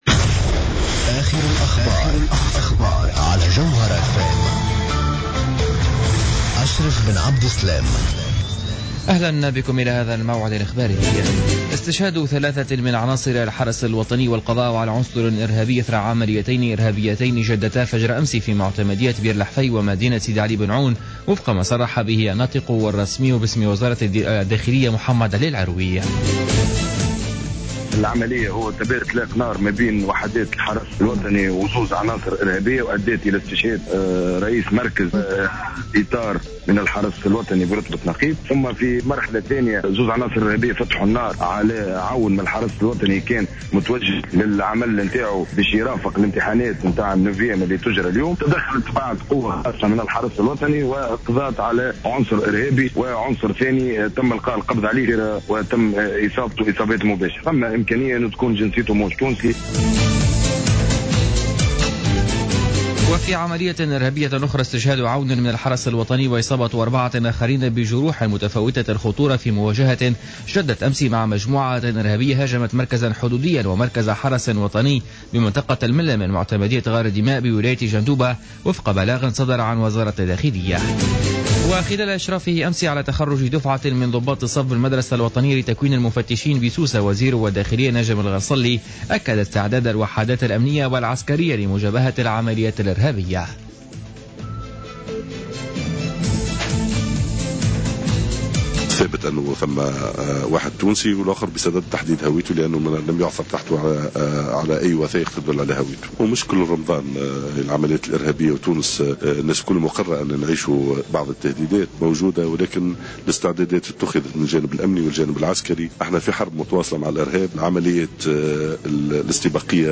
نشرة أخبار منتصف الليل ليوم الثلاثاء 16 جوان 2015